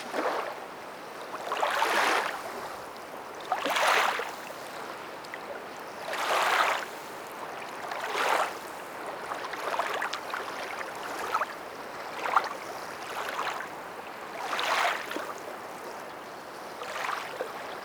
LAKE LAP 01R.wav